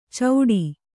♪ cauḍi